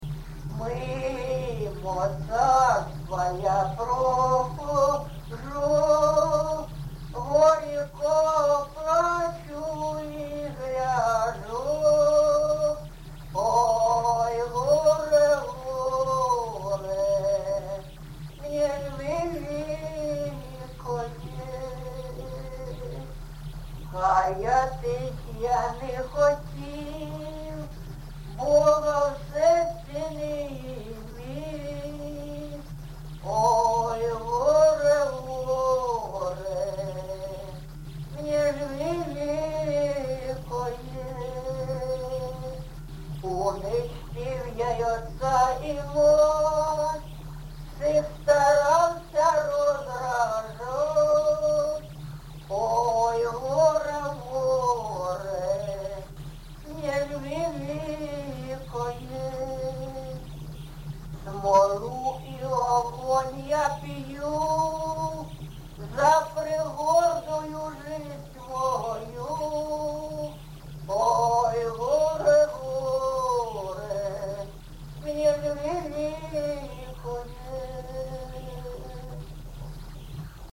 ЖанрПсальми
Місце записус. Софіївка, Краматорський район, Донецька обл., Україна, Слобожанщина